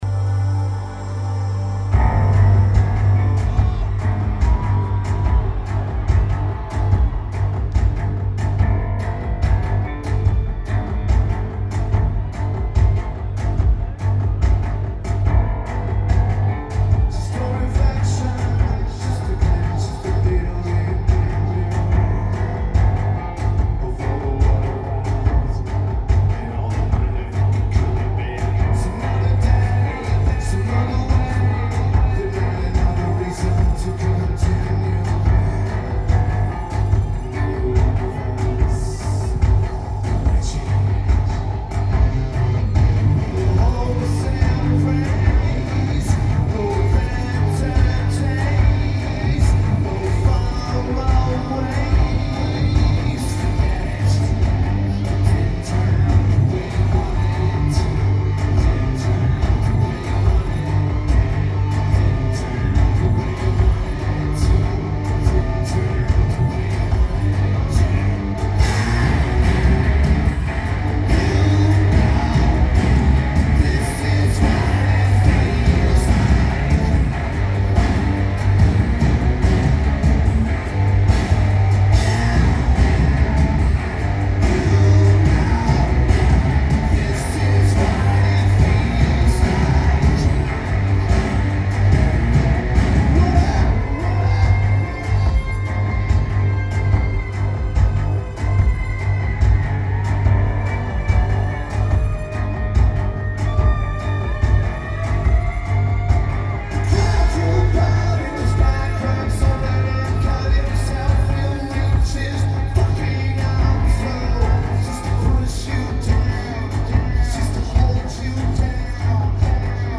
Starlake Amphitheater
The recording is great.